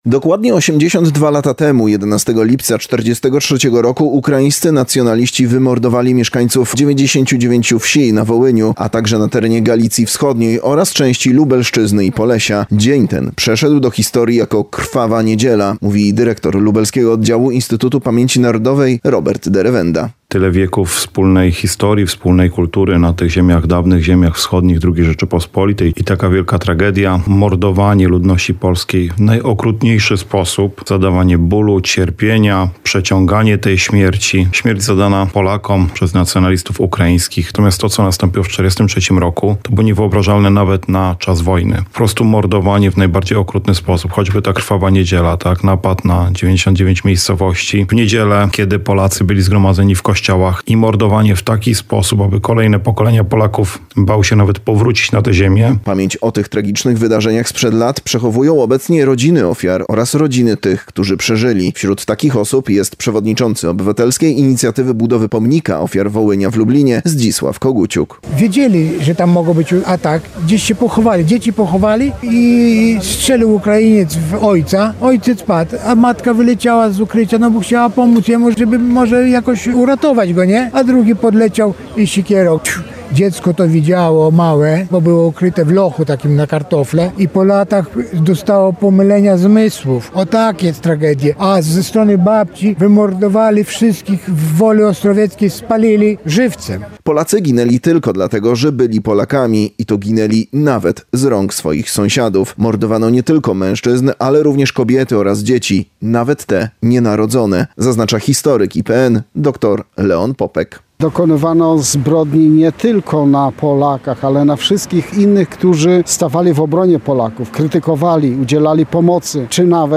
A 11_07 16_24 Wołyń - uroczystości